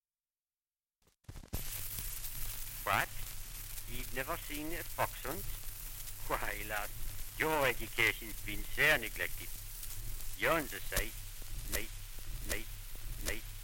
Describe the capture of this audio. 78 r.p.m., cellulose nitrate on aluminium.